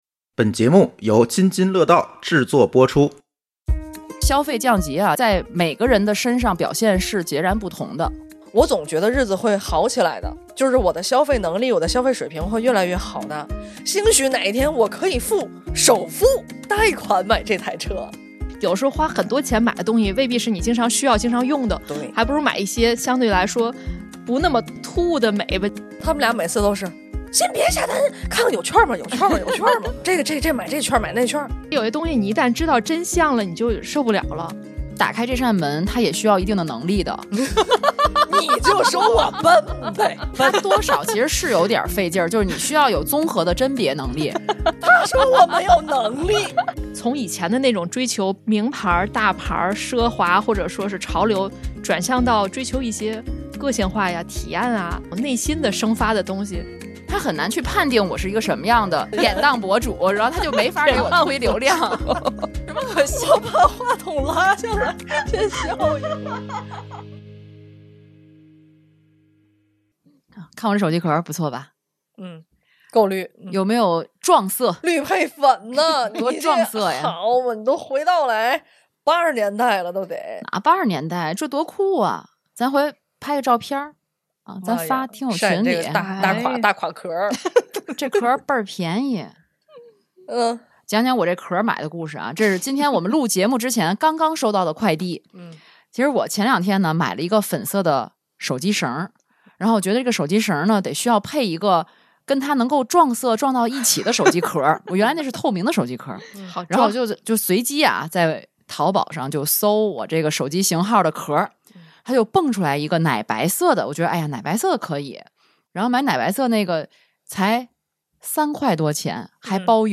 本期节目里，三位主播掏心窝子，分享如何在消费降级的大环境下，依然保持生活品质不降反升的小经验、小窍门。
场地支持 / 声湃轩天津录音间